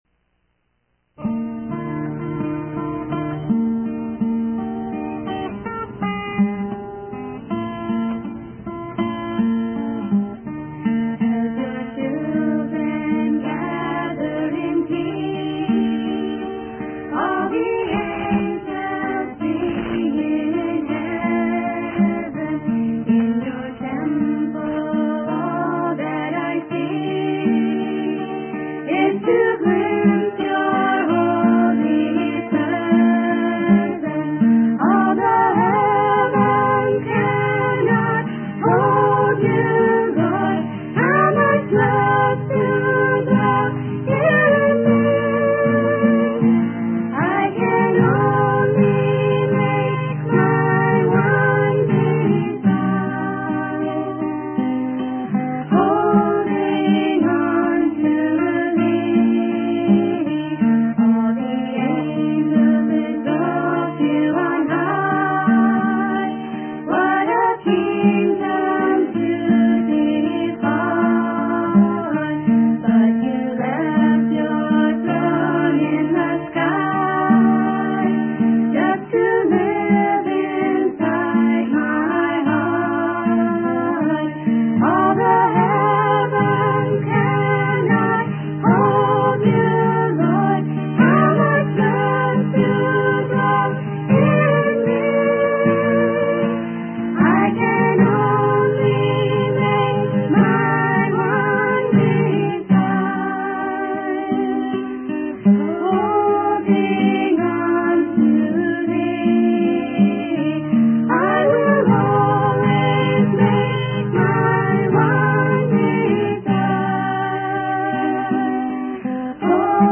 In this sermon, the preacher emphasizes the importance of honoring Jesus Christ, the Son of God, who suffered judgment and wrath on behalf of humanity.